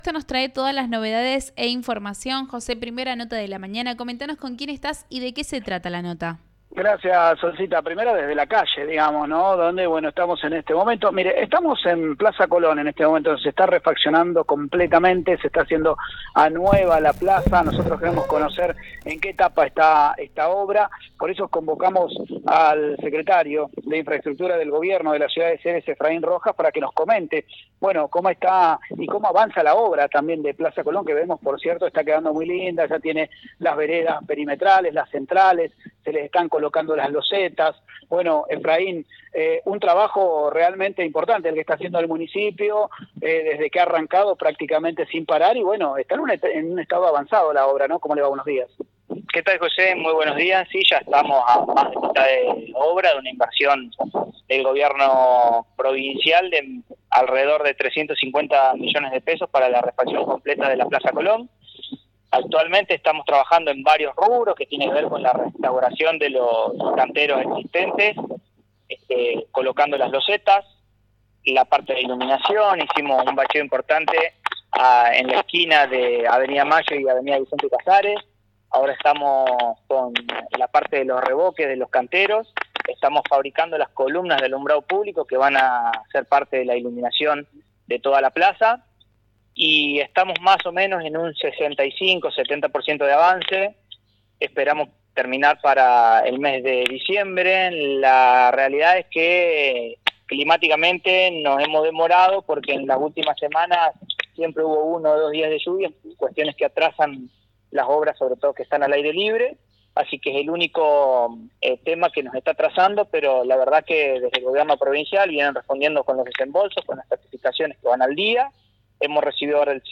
En una entrevista que realizamos con el secretario de Infraestructura Efrain Rojas detalló todos los frentes abiertos en obras que lleva adelante el Gobierno de Alejandra Dupouy.